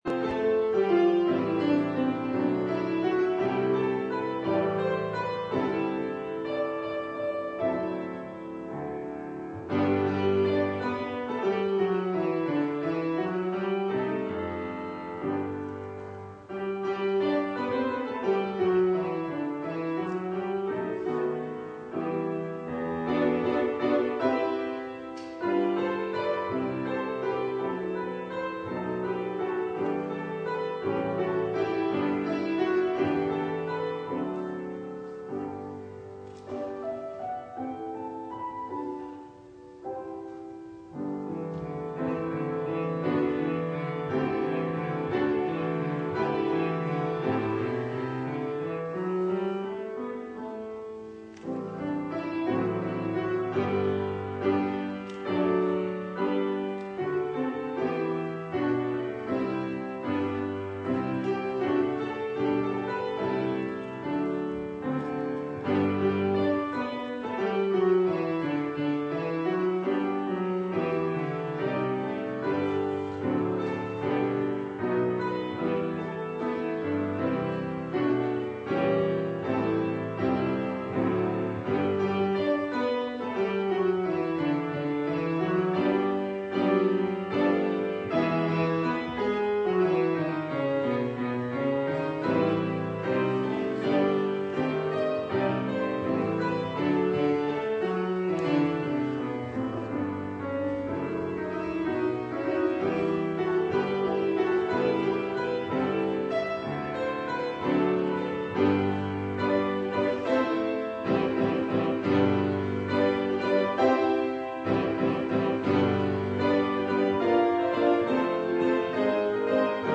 Single Sermons Passage: Amos 8:1-14 Service Type: Morning